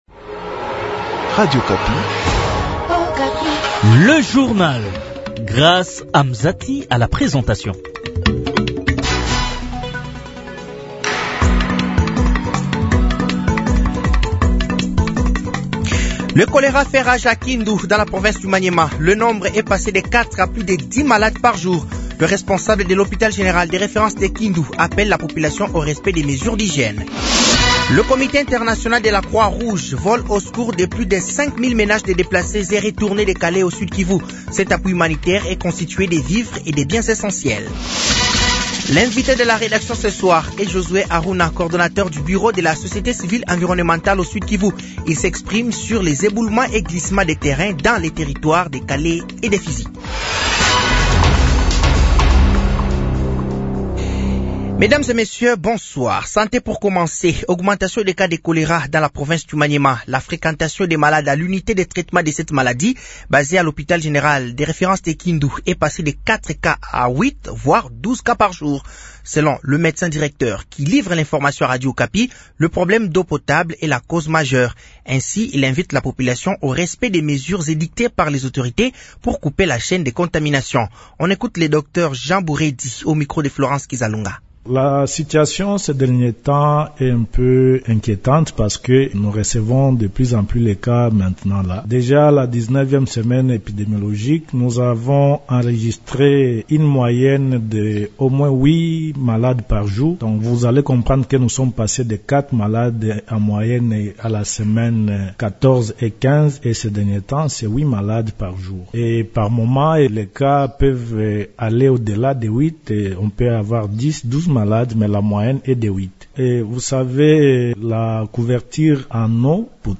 Journal français de 18h de ce mercredi 14 mai 2025